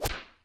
Slap.ogg